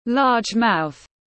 Miệng rộng tiếng anh gọi là large mouth, phiên âm tiếng anh đọc là /lɑːdʒ maʊθ/ .